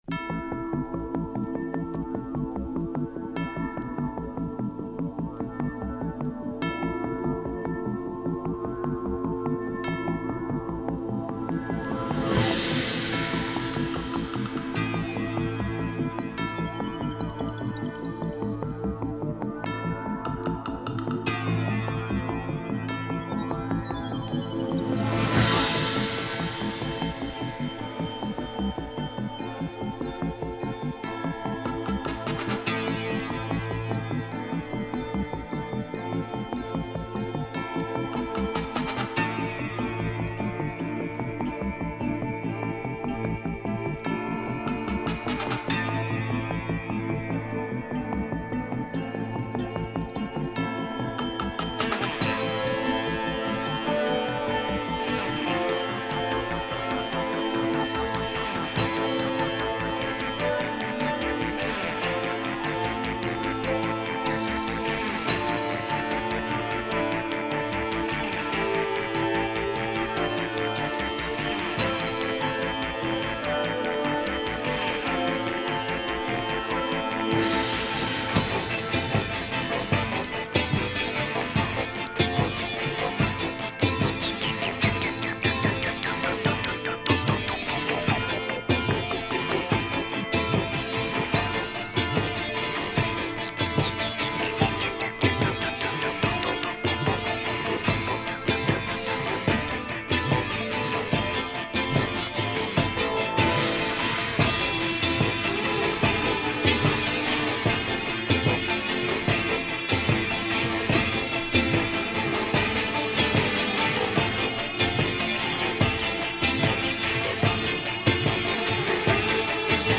Synth pop discoid and early ealectronics